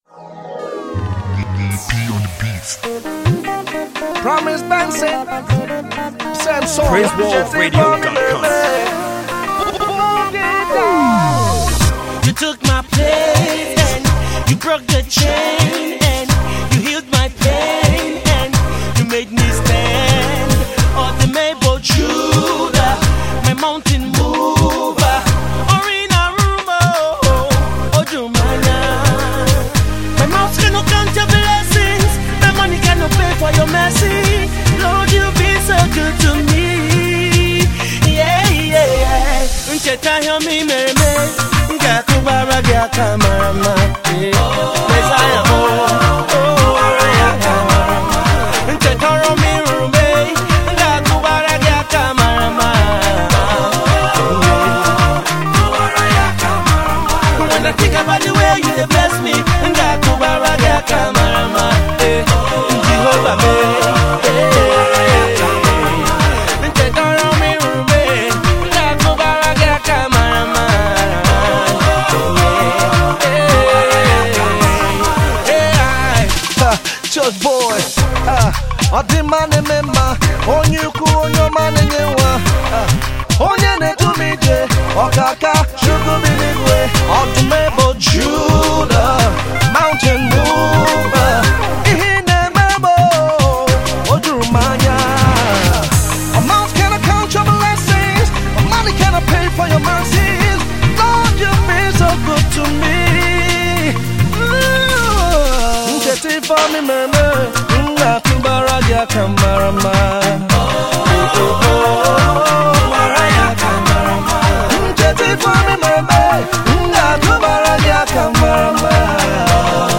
energetic and melodious song